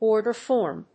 órder fòrm